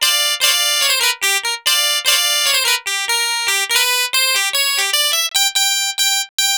Twisting 2Nite 6 Clav-G.wav